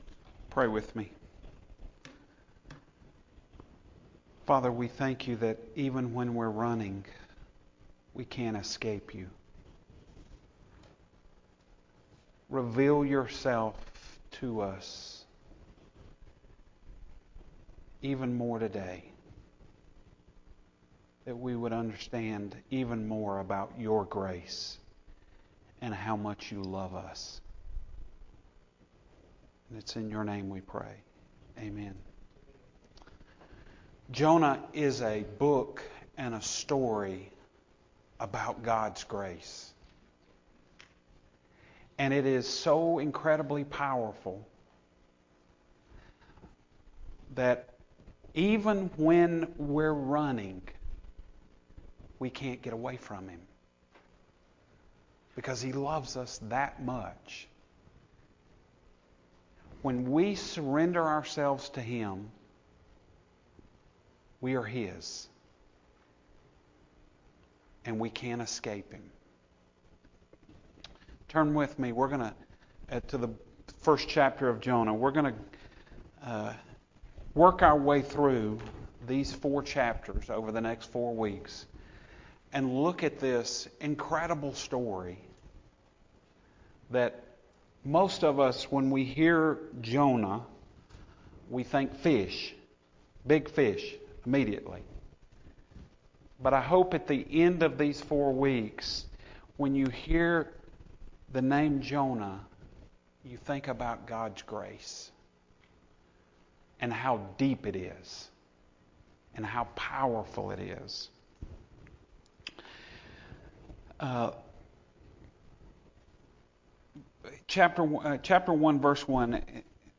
Sermon Only